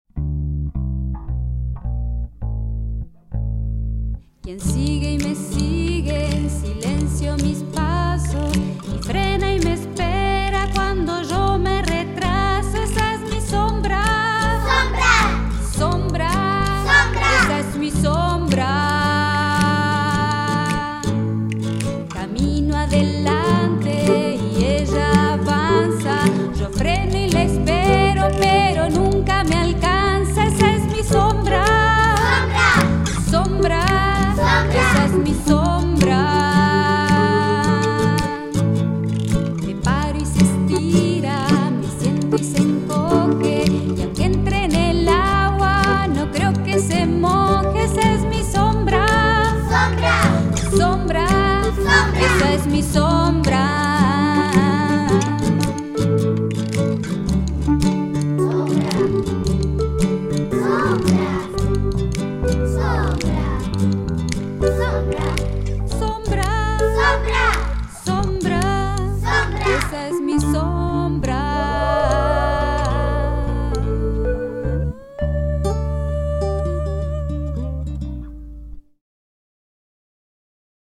es una canción para trabajar el eco rítmico y melódico